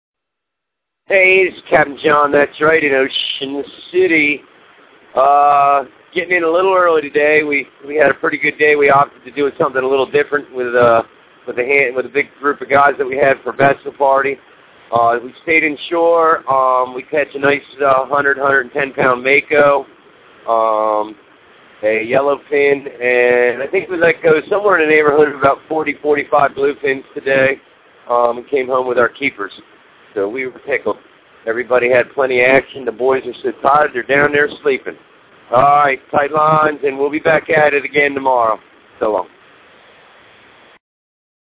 Shark/Tuna Audio Fishing report here.